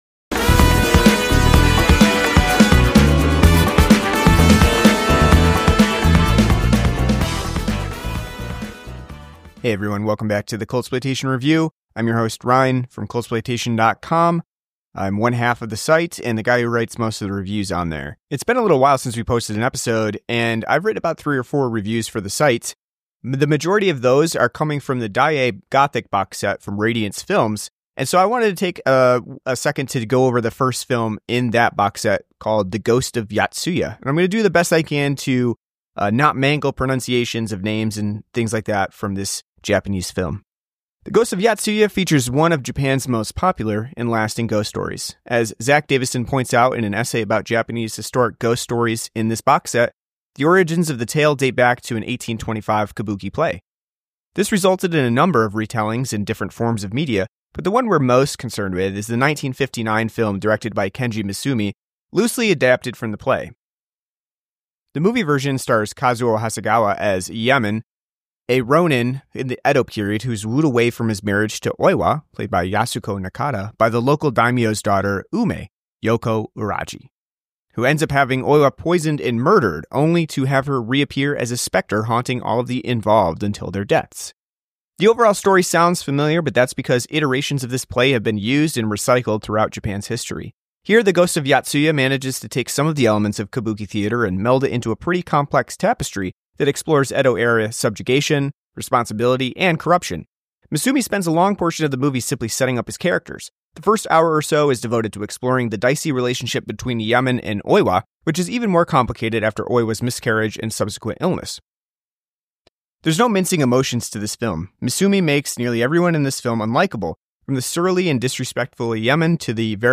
An audio review of The Ghost of Yotsuya on Blu-ray as part of Radiance Films' Daiei Gothic boxset.